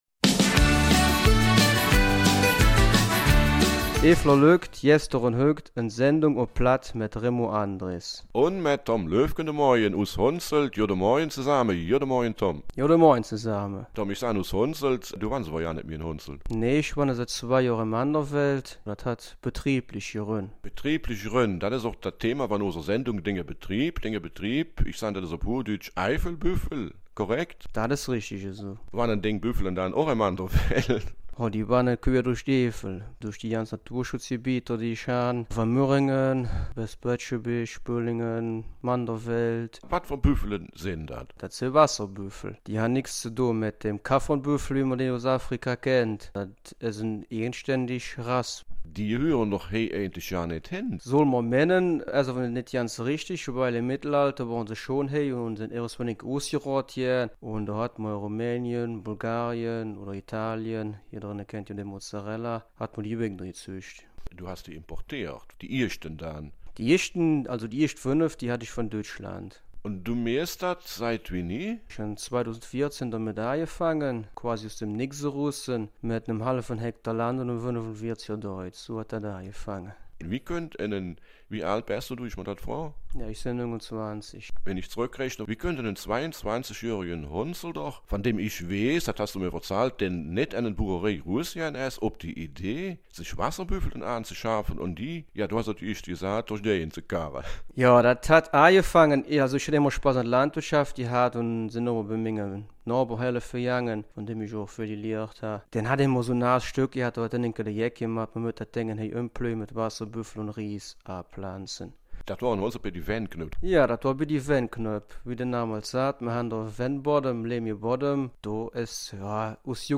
Eifeler Mundart - 24. Oktober